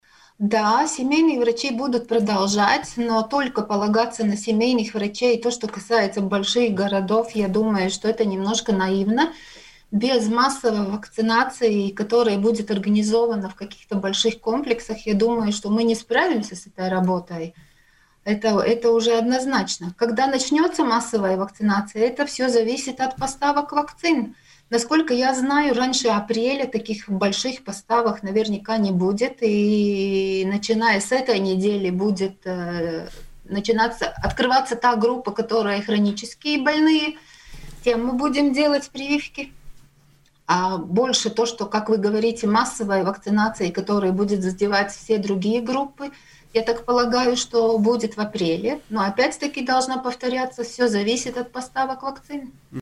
Об этом в эфире радио Baltkom рассказала Член правления Латвийской ассоциации семейных врачей